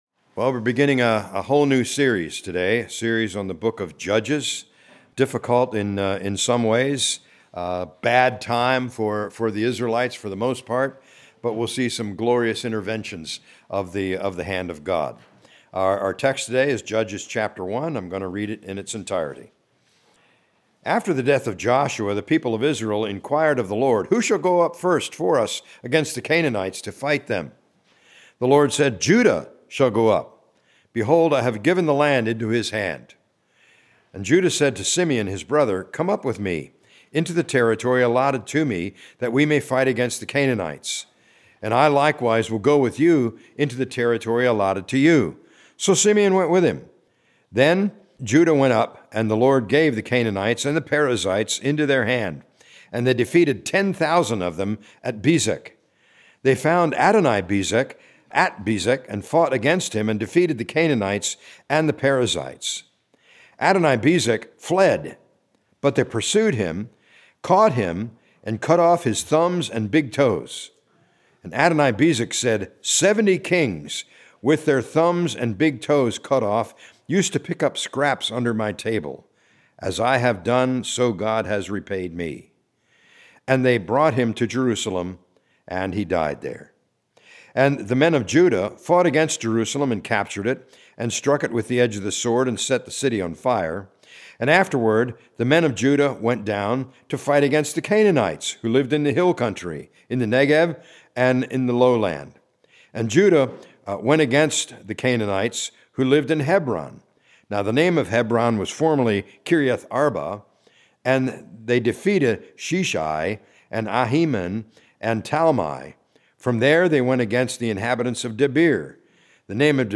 A message from the series "Judges 2025."